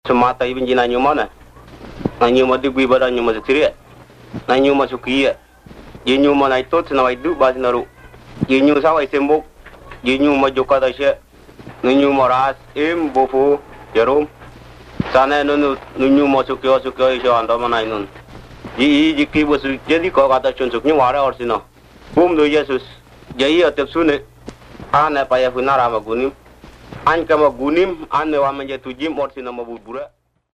These are recorded by mother-tongue speakers